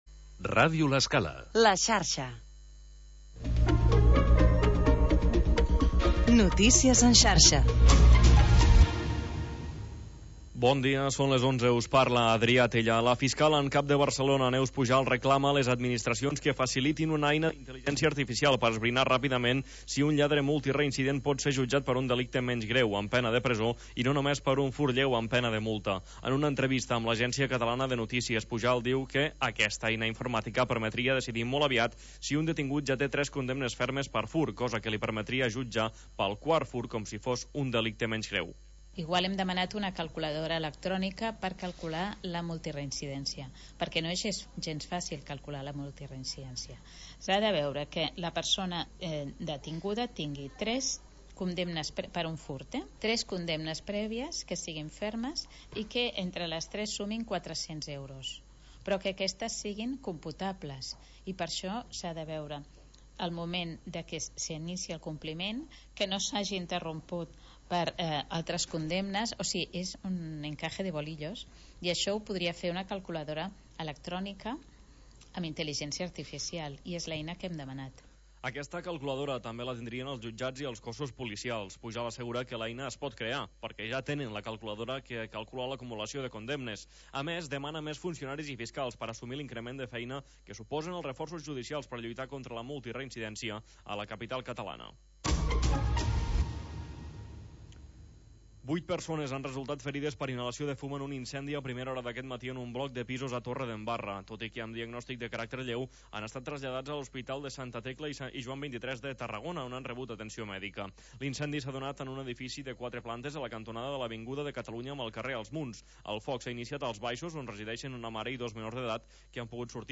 Havanera, cant de taverna i cançó marinera. 15 anys obrint una finestra al mar per deixar entrar els sons més mariners